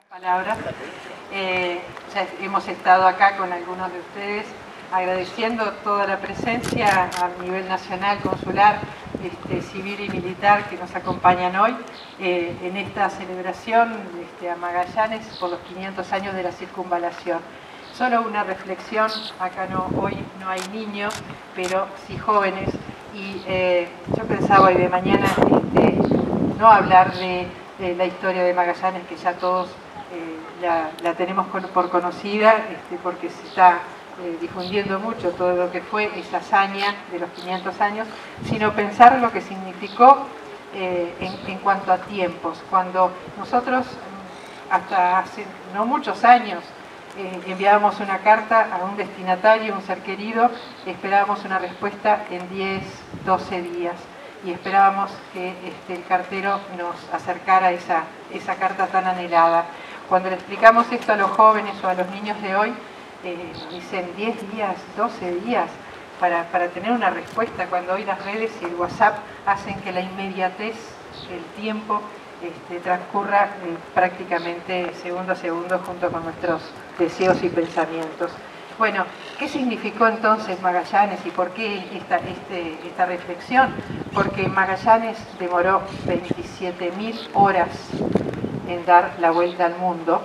Posteriormente en la Punta de San Pedro, se llevó a cabo un acto en el que se descubrió una placa conmemorativa a Magallanes, al pie del mástil del Pabellón Nacional.
Embajador de Portugal